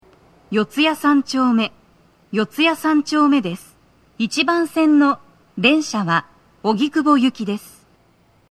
スピーカー種類 TOA天井型()
🎵到着放送
足元注意喚起放送の付帯は無く、フルの難易度は普通です
女声